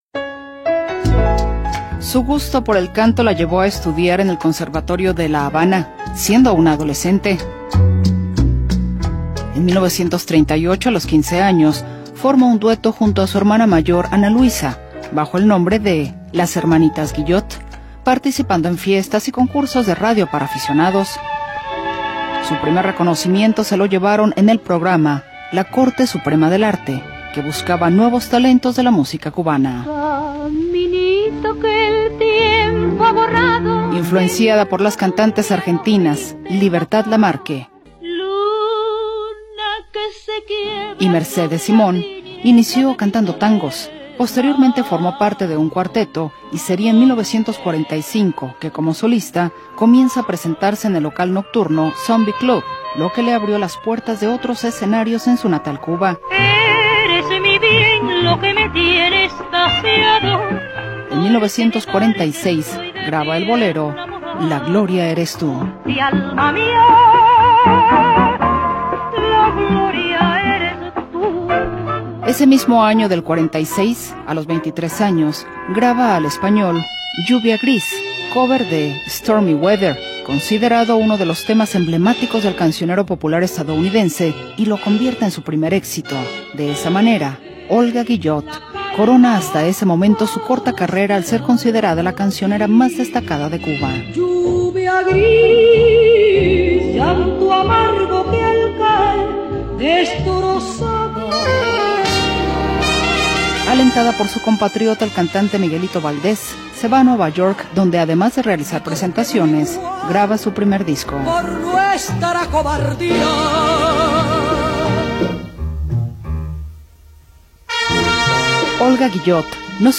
cantante cubana de boleros